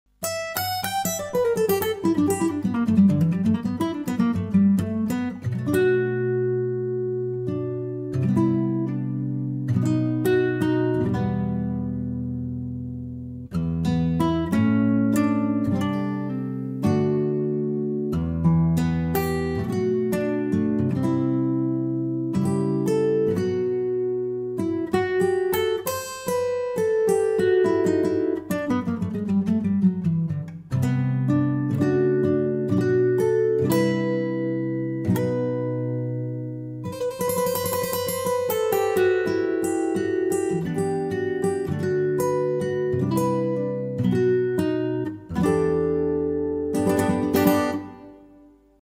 Ample Guitar Luthier